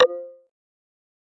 科幻网络过渡音效
描述：计算机或机器人中两种状态的简单转换。也许是gui的声音。在Audacity中使用语音和时间操作进行制作。
标签： GUI 过渡 科幻 音效 网络 极简主义 简单
声道立体声